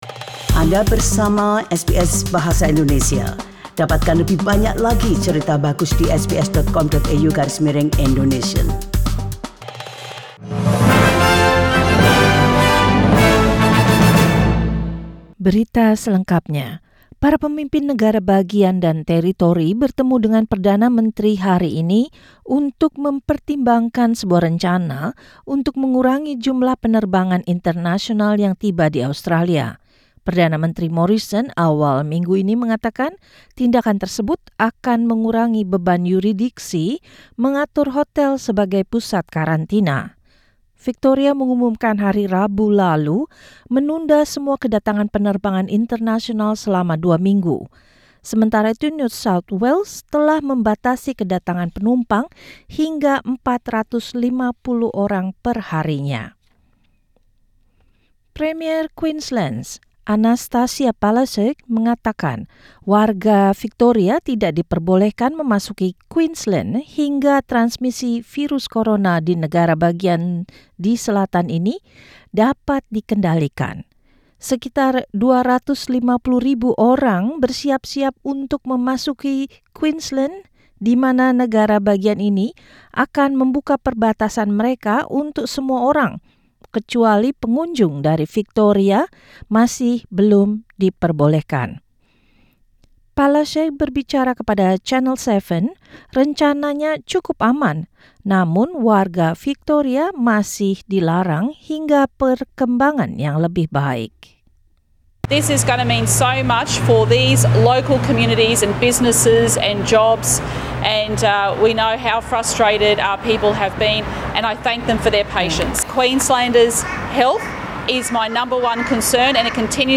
SBS Radio News in Indonesian, 10 July 2020